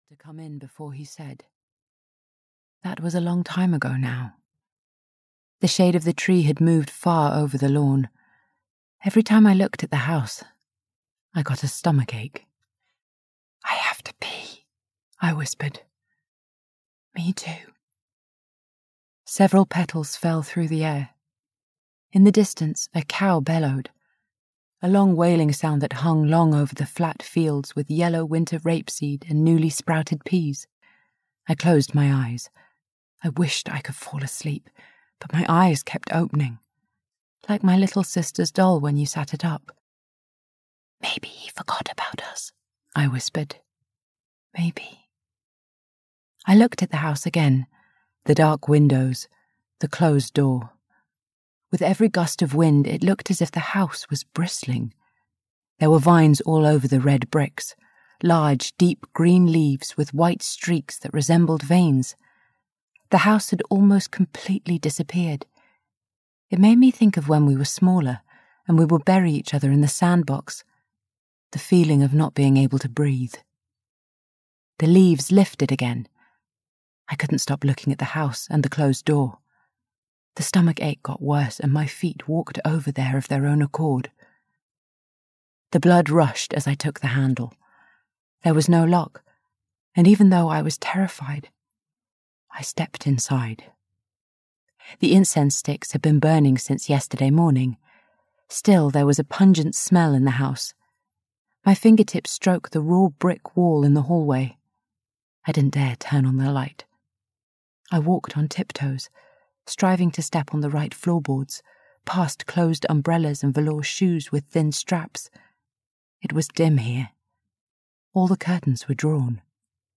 The Chinese Twin (EN) audiokniha
Ukázka z knihy